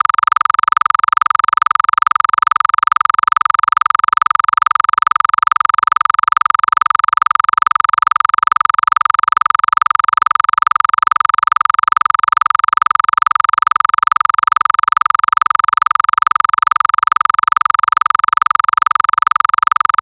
• Erstelle Pulspakete aus einem Sinussignal von ca. 1,5 kHz.
• Jedes Paket enthält etwa 8 bis 10 Sinusschwingungen, sodass die Dauer kurz bleibt
• Zwischen den Pulspaketen eine längere Pause einfügen, um die mittlere Leistung zu reduzieren
Ein- und Ausblendungen am Anfang und Ende eines Pakets vermeiden harte Klicks.
• Das fertige Signal wird als WAV Datei mit 8 KHz Abtastrate exportiert